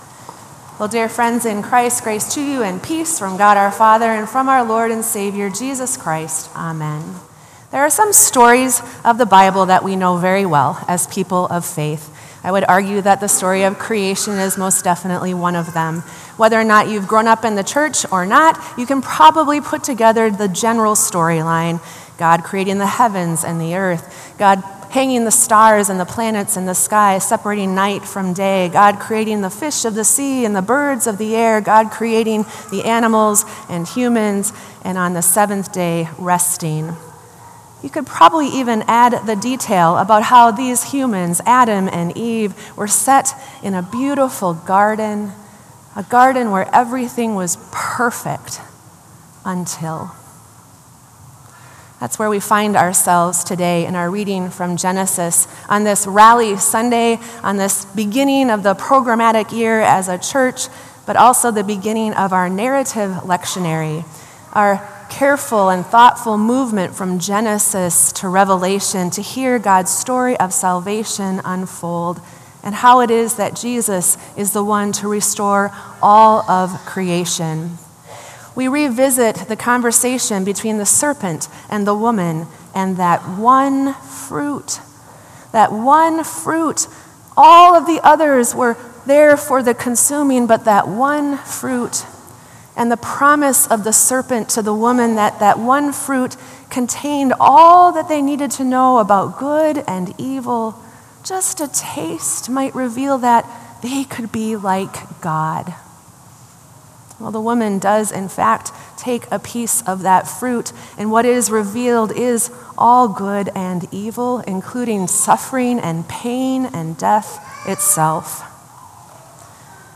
Sermon “Oh, The Shame”